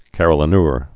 (kărə-lə-nûr)